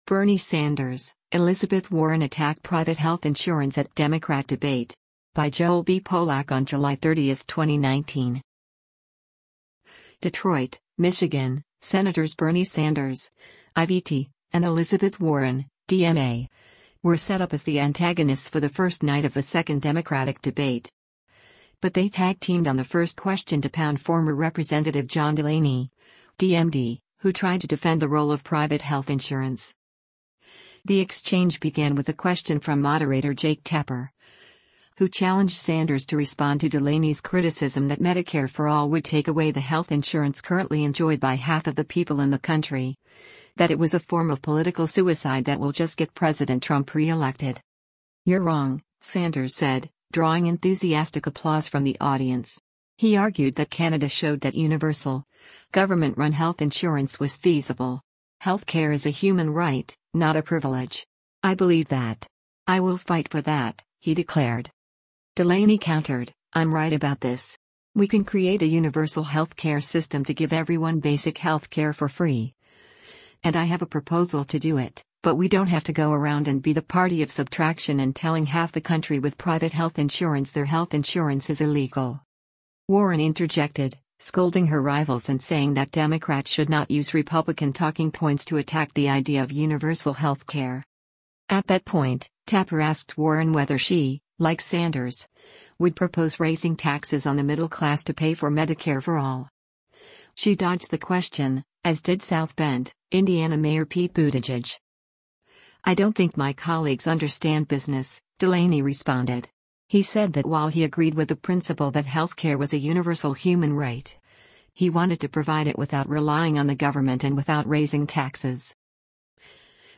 “You’re wrong,” Sanders said, drawing enthusiastic applause from the audience.
Buttigieg drew applause when he said that Democrats should stop worrying that Republicans would call them “a bunch of crazy socialists,” and just “stand up for the right policy.”